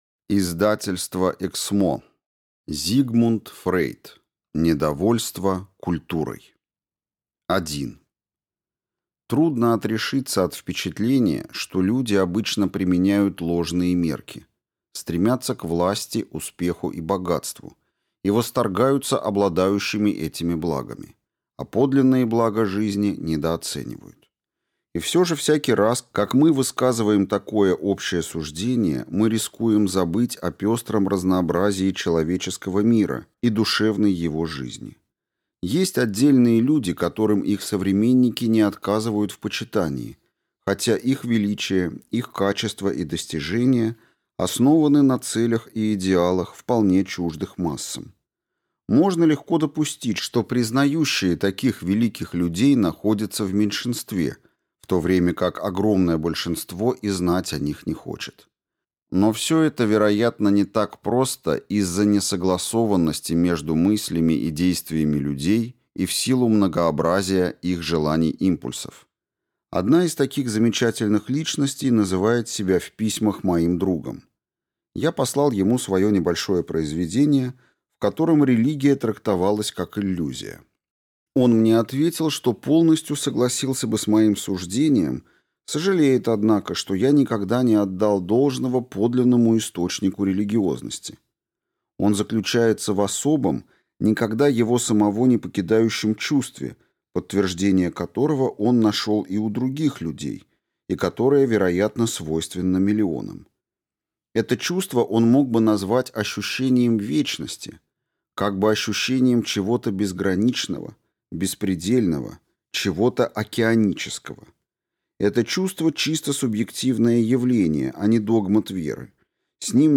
Аудиокнига Недовольство культурой | Библиотека аудиокниг